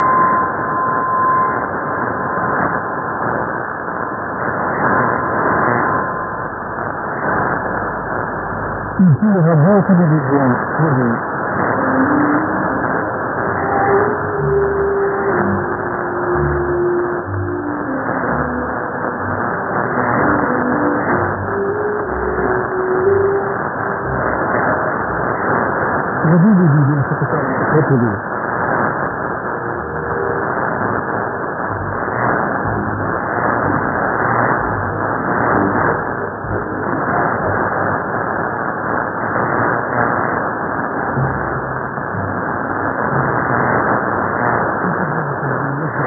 ・このＨＰに載ってい音声(ＩＳとＩＤ等)は、当家(POST No. 488-xxxx)愛知県尾張旭市で受信した物です。